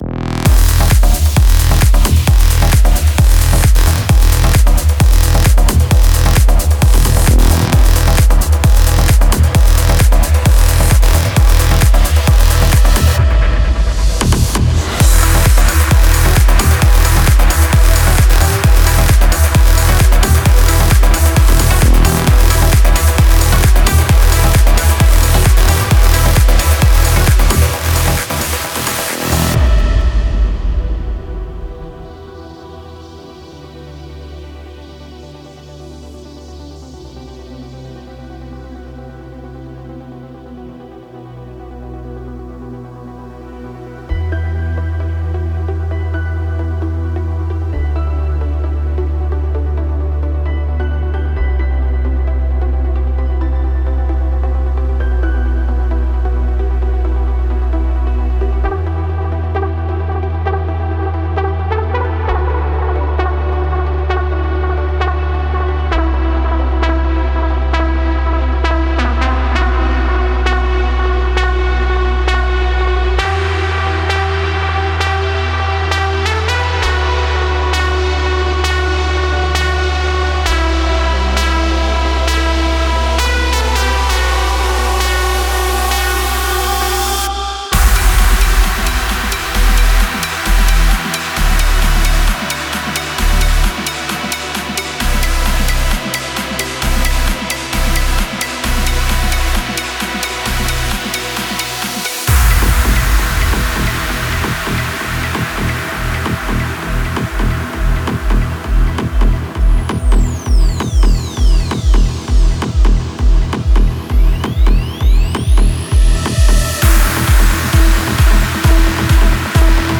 это яркое произведение в жанре прогрессив-хаус